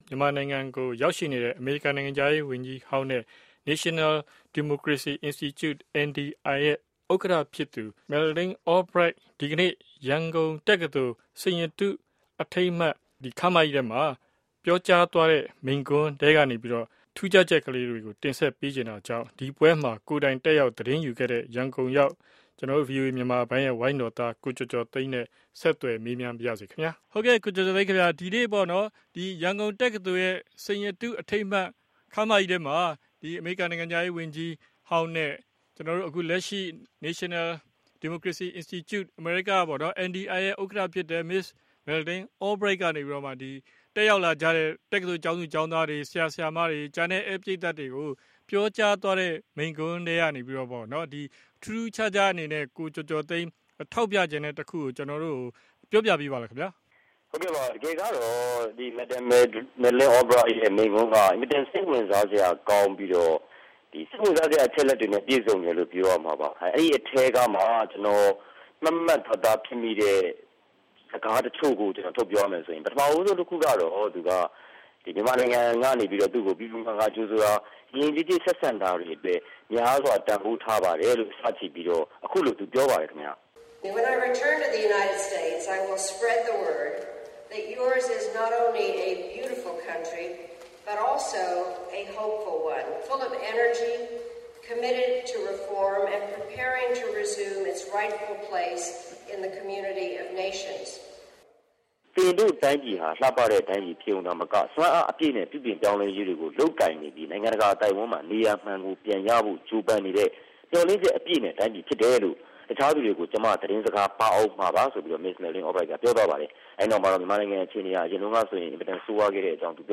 ရန်ကုန်တက္ကသိုလ်မိန့်ခွန်း